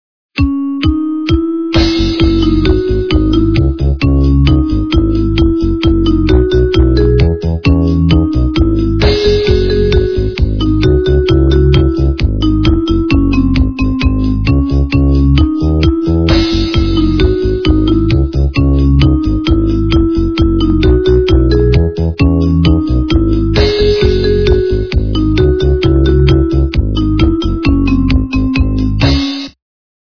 качество понижено и присутствуют гудки
полифоническую мелодию